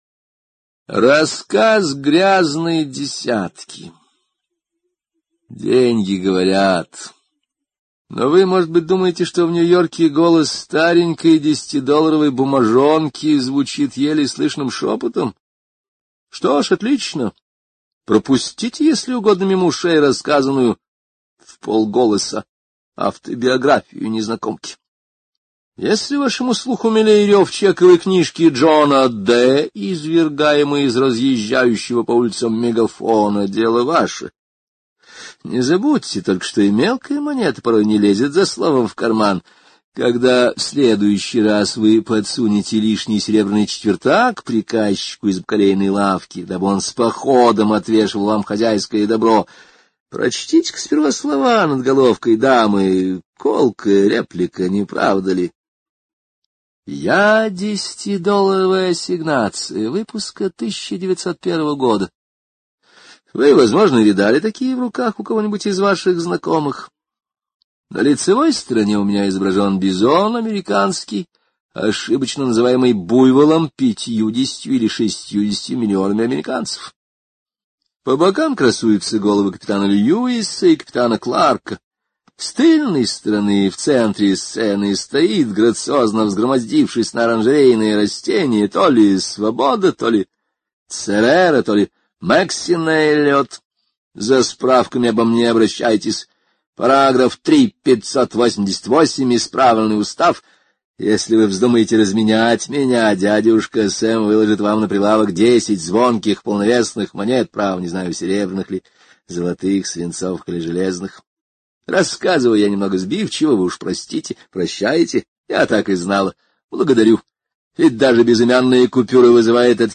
Рассказ грязной десятки — слушать аудиосказку Генри О бесплатно онлайн